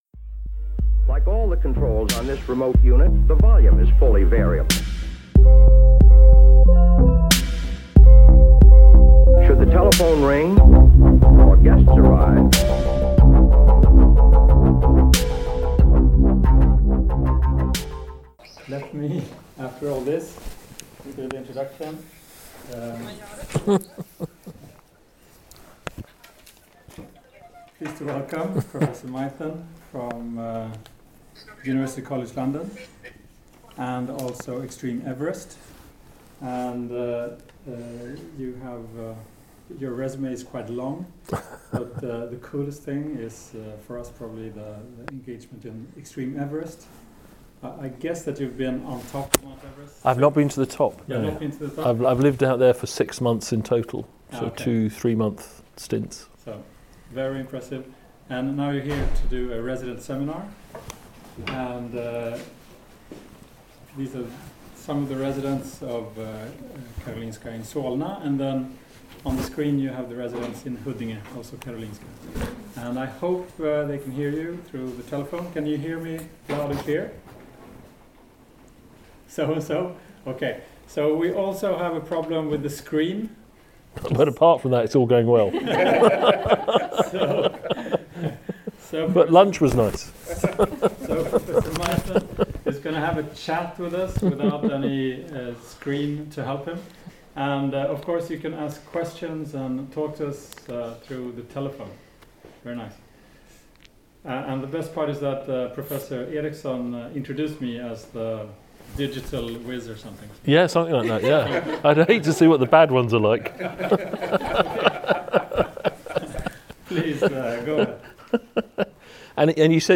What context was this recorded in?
The recording was made in Karolinska Solna on the 28th of November 2018, with residents in Huddinge participating in a faulty video conference call.